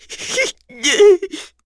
Ezekiel-vox-Sad_kr.wav